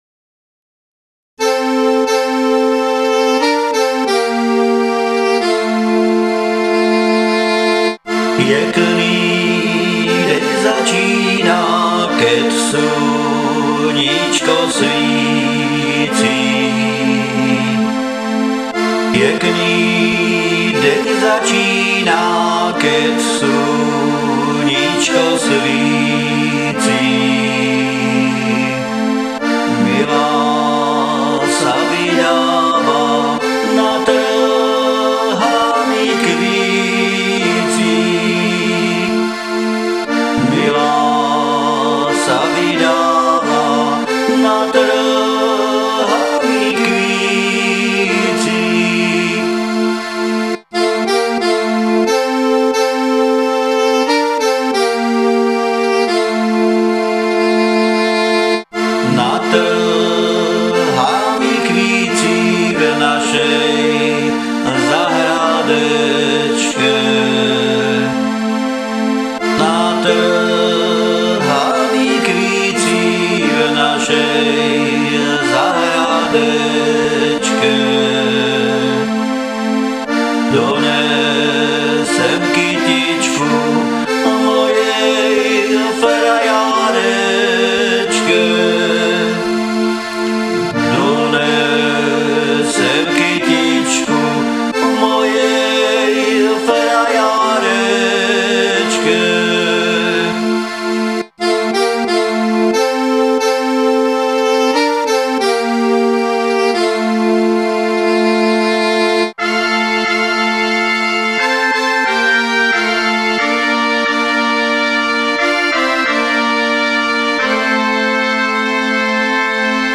Som amatérsky muzikant, skladám piesne väčšinou v "záhoráčtine" a tu ich budem prezentovať.